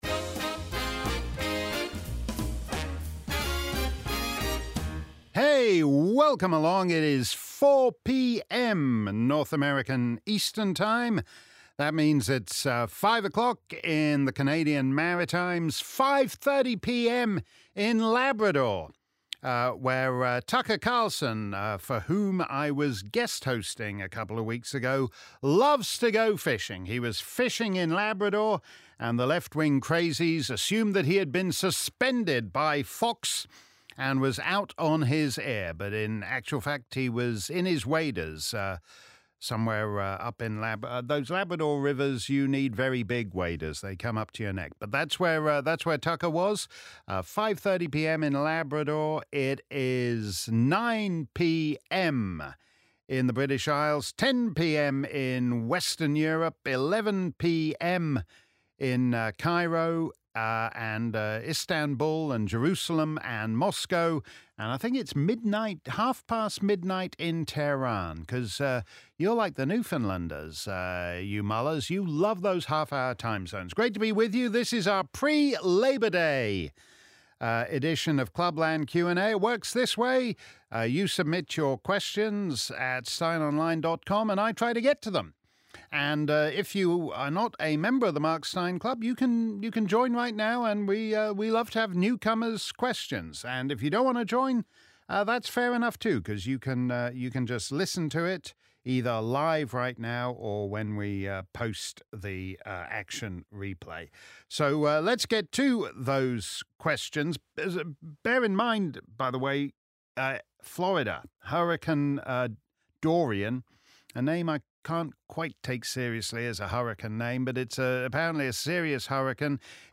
If you missed our livestream Clubland Q&A on Friday afternoon, here's the action replay. Simply click above and settle back for an hour of my answers to questions from Mark Steyn Club members around the planet.